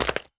Chopping and Mining
mine 2.wav